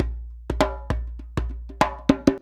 100DJEMB31.wav